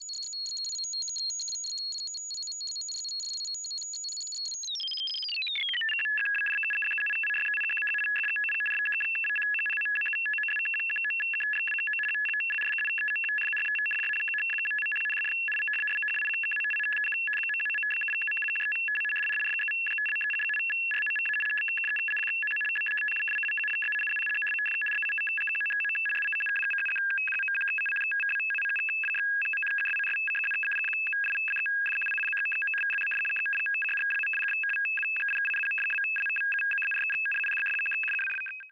Morse Code: Synthesized, Multiple Speeds And Effects. Mono